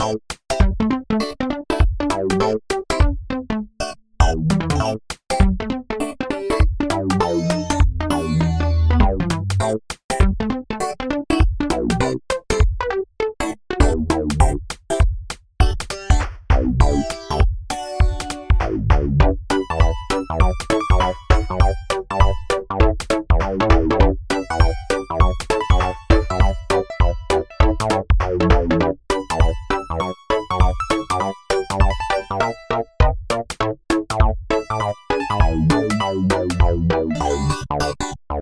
funky theme